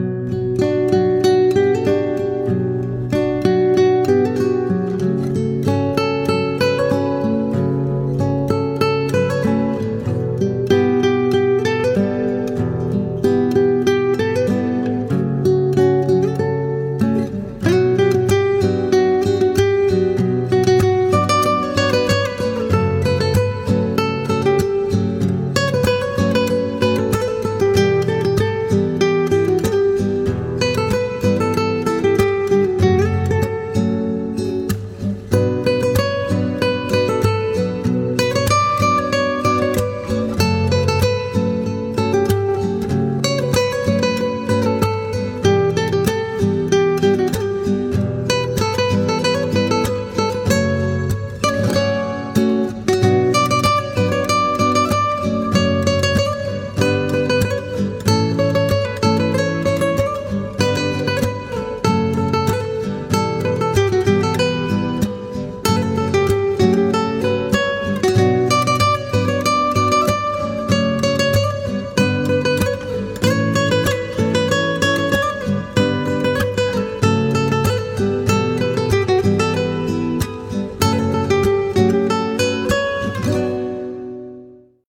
اهنگ زنگ گیتار آرامبخش